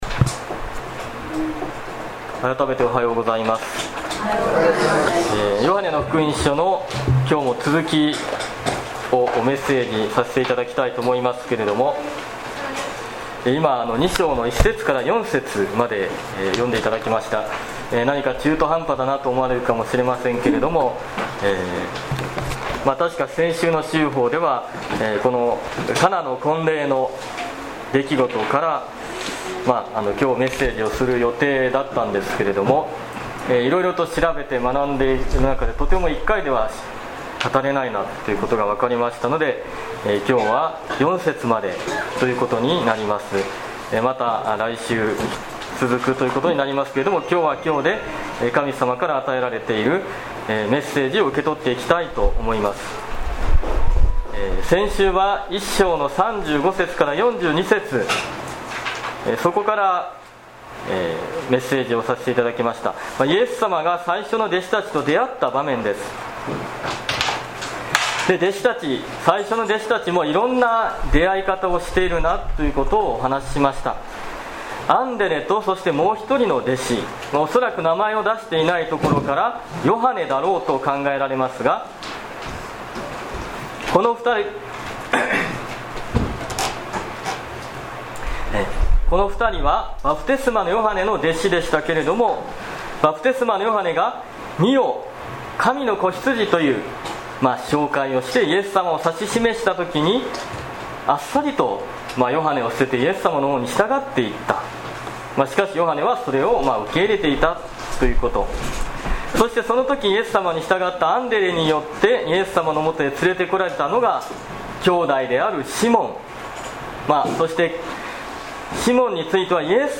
2017年７月30日礼拝メッセージ